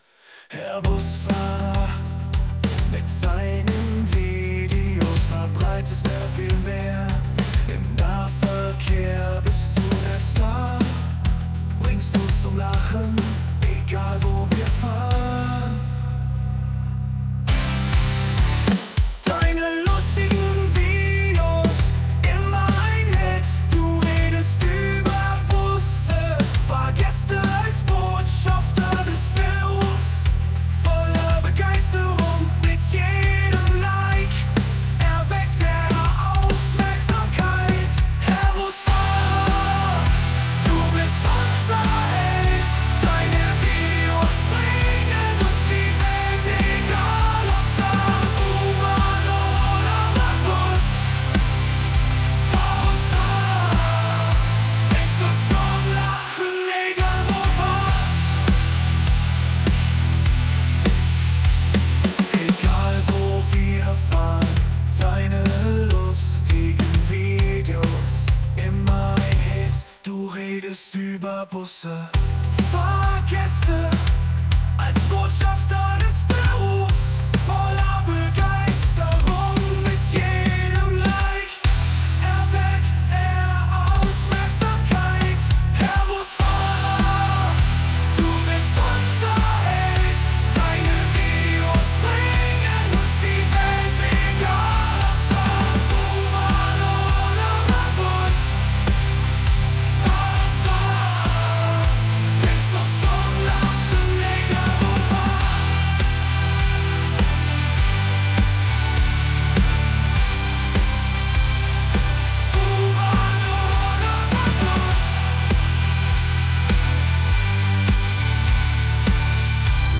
DOWNLOAD Song: Herr Busfahrer Rock Version